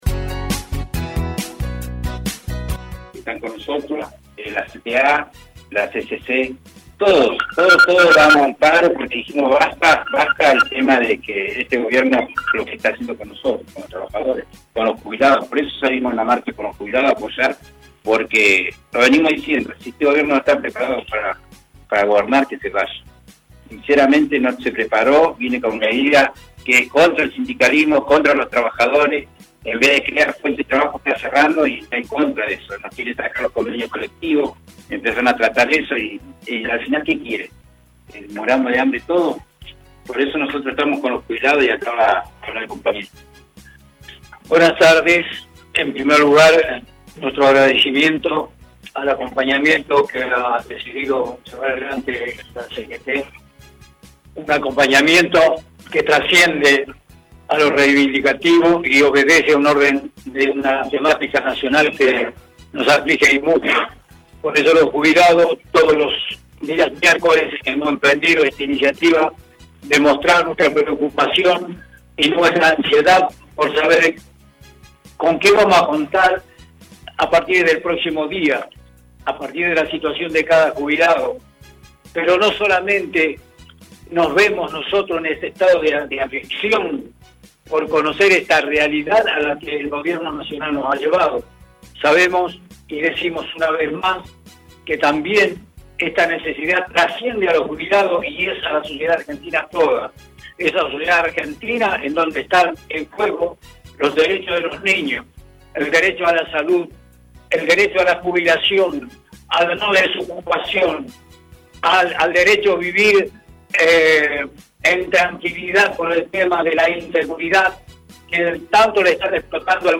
Los conceptos de la conferencia de prensa: